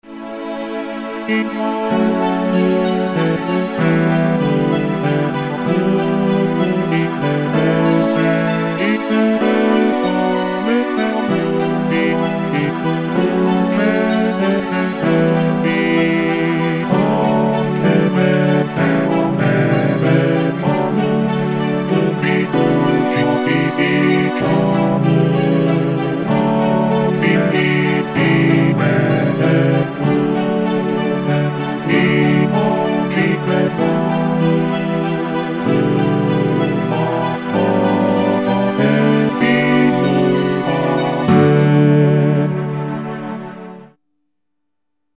Mp3 – Demo digitale